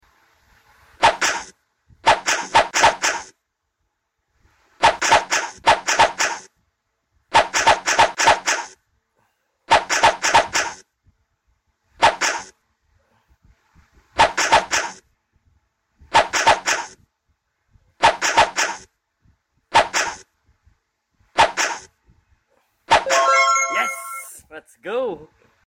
Solid Hahahaha Sound Effects Free Download